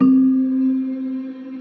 Bell - Lost.wav